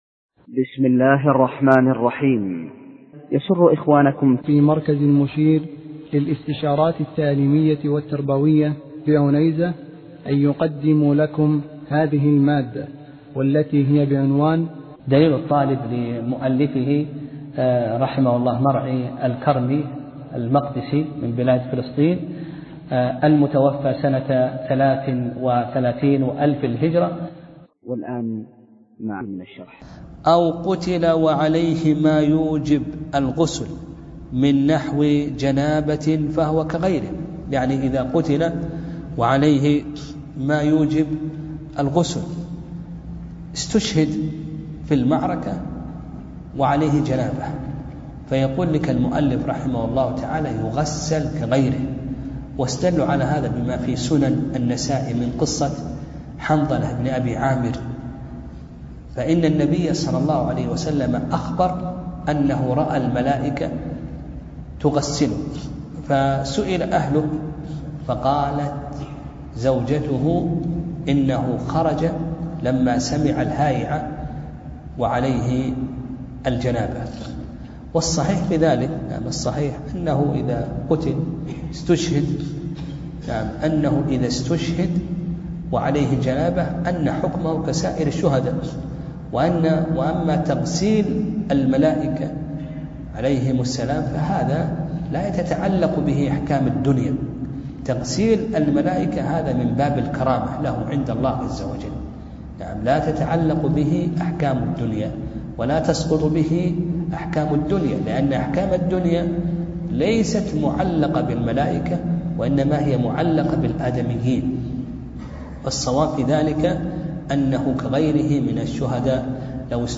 درس (27) : كتاب الجنائز (3)